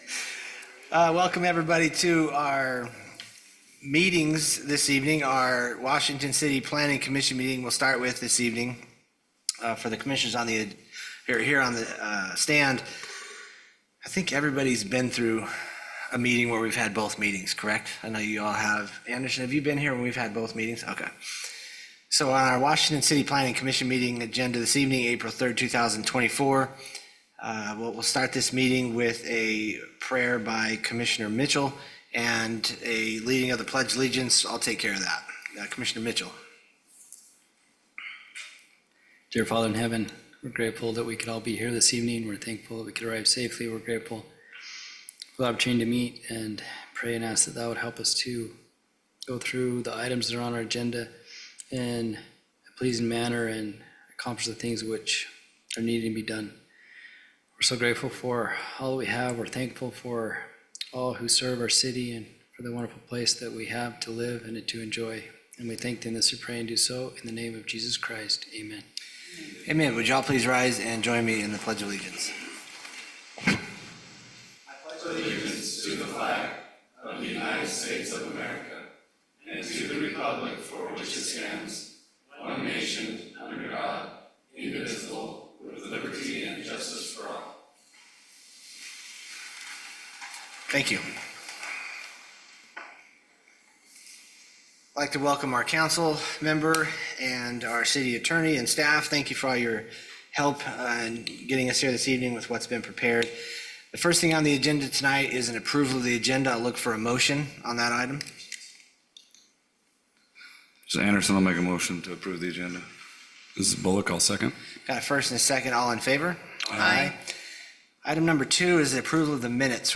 Notice, Meeting, Hearing
Washington City Planning Commission Regular Meeting Agenda April 3, 2024 PUBLIC NOTICE is hereby given that the Planning Commission of Washington City will host a public electronic meeting on Wednesday, April 3, 2024 at 6:00 P.M. The Planning Commission will be held in the Council Chambers of Washington City Hall located at 111 North 100 East, Washington, Utah.